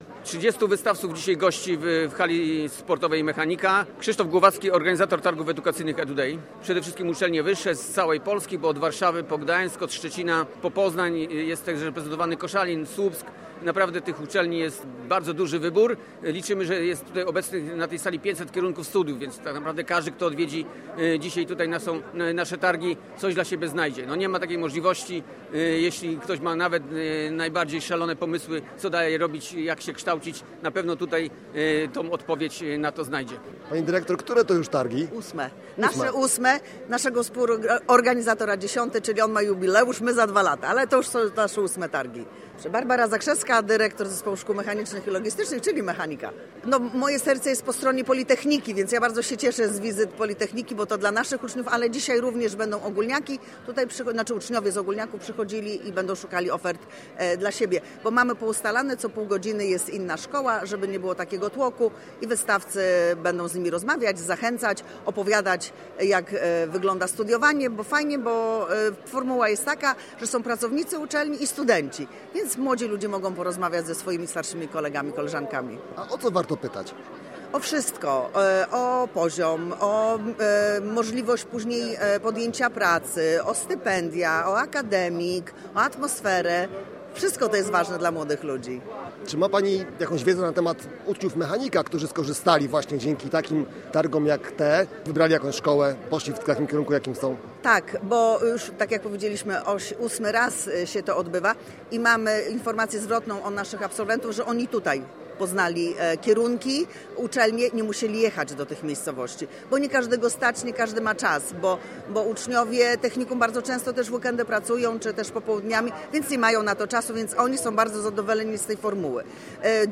Posłuchaj relacji z wydarzenia: